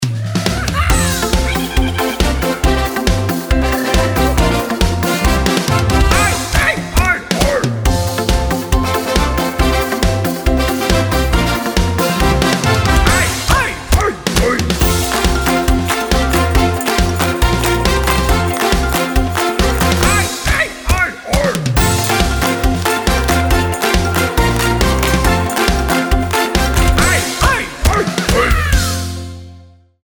dansmuziek maakt het feest compleet.
chiapasdisco31314.mp3